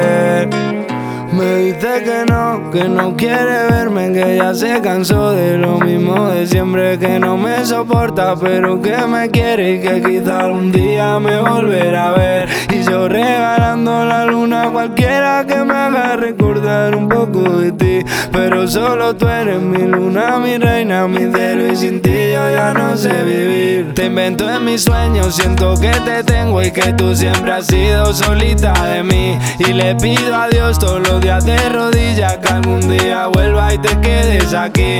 Жанр: Поп
# Pop in Spanish